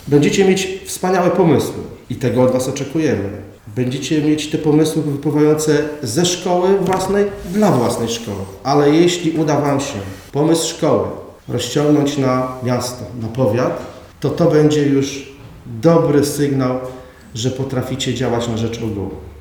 – Cieszę się, że wracamy do idei samorządu wśród młodzieży – powiedział obecny na inauguracyjnej sesji przewodniczący „dorosłej” Rady Miejskiej Cezary Piórkowski.